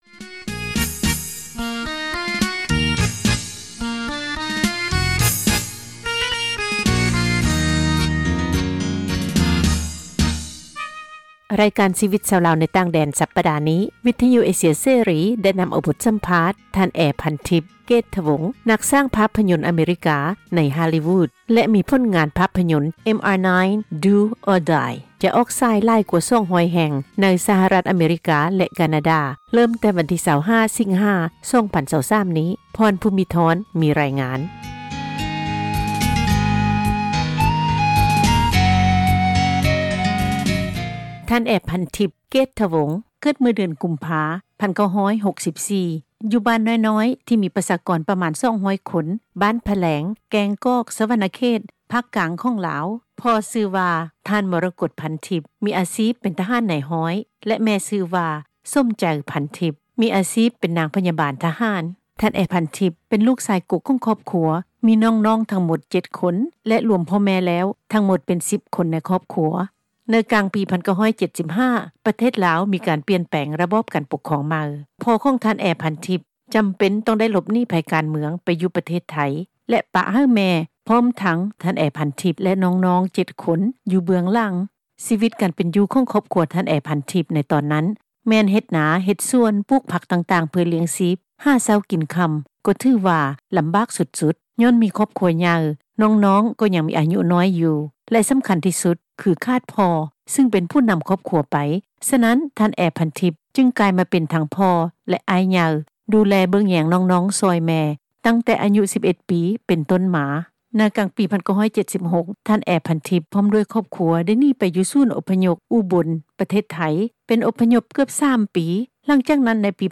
ສັມພາດ ນັກສ້າງພາພຍົນ ໃນຮໍລ໌ລີວູດ (Hollywood)